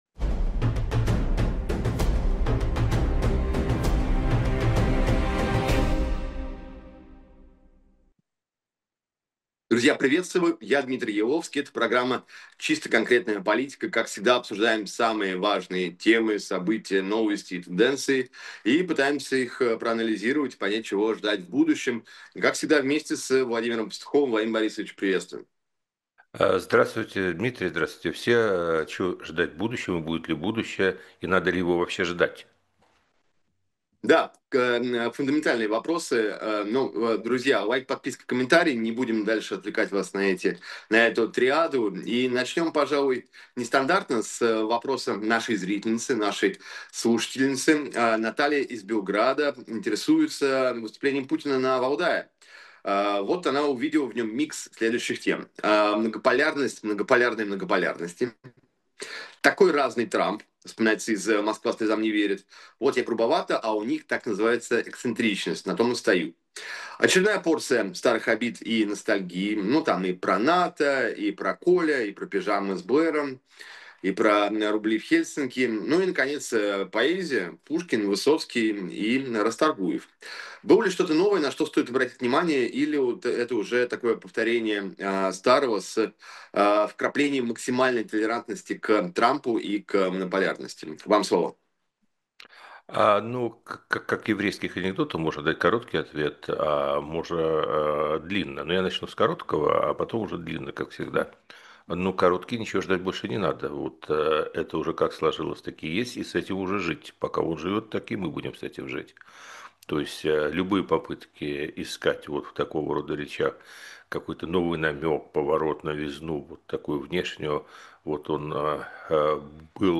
Владимир Пастухов политолог